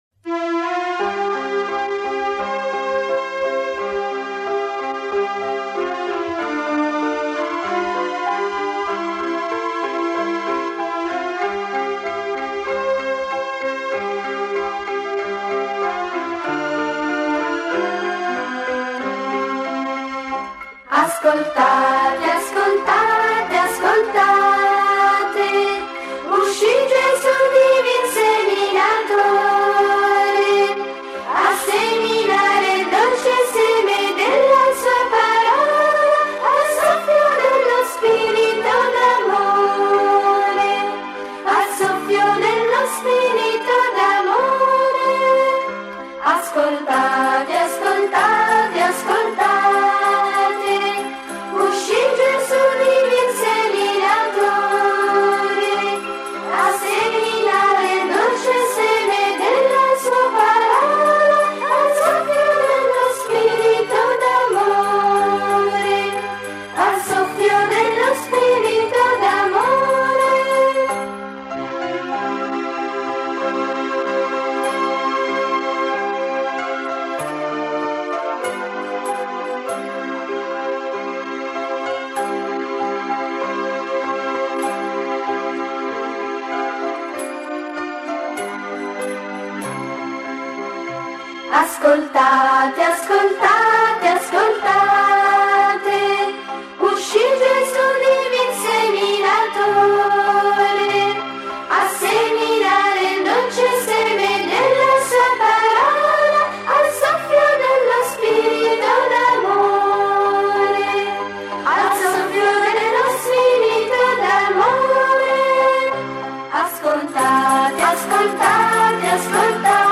Canto per la Decina di Rosario e Parola di Dio: O Dio, mio Re Canto del Salmo 125: Ascoltate, uscì Gesù divin seminatore Related Posts Tags Share This